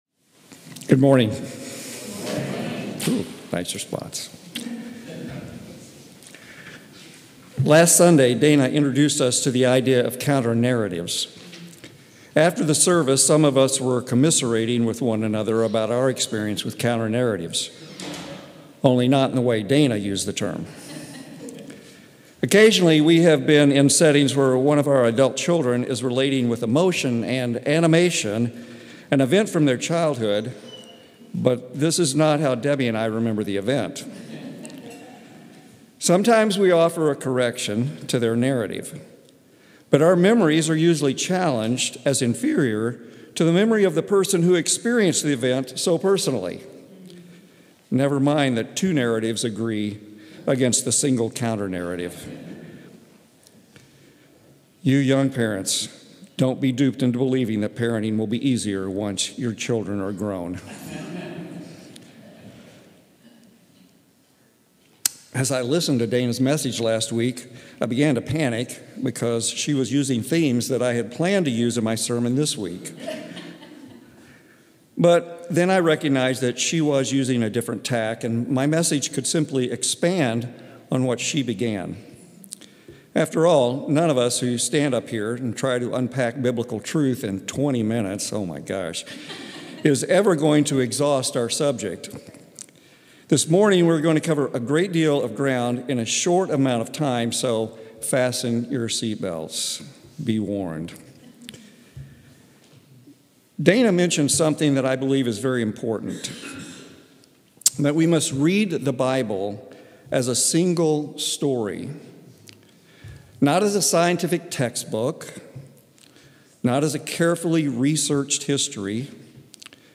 Sunday, May 11, 2025, Evening Service